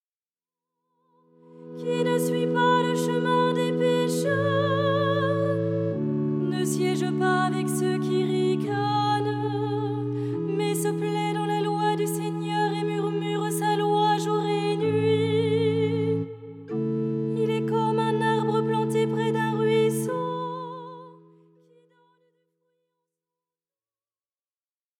style simple et chantant